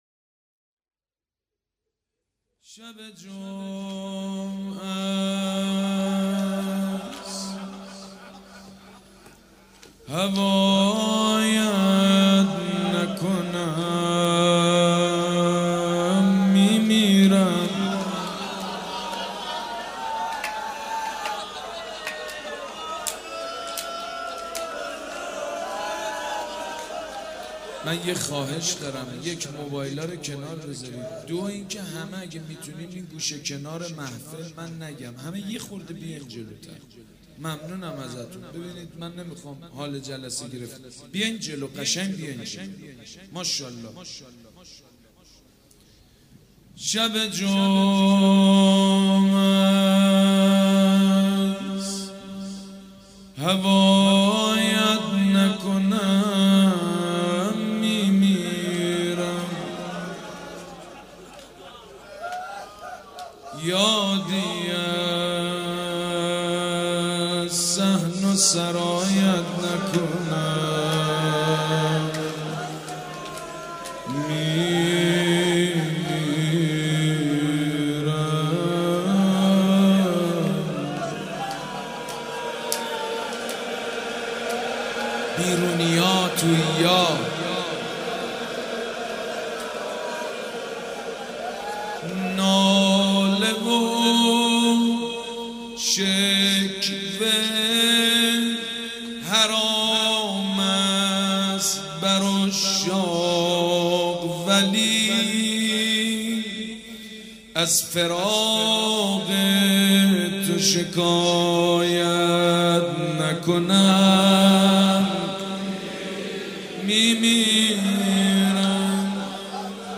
روضه
شب پنجم محرم الحرام‌ پنجشنبه ۱5 مهرماه ۱۳۹۵ هيئت ريحانة الحسين(س)
مداح حاج سید مجید بنی فاطمه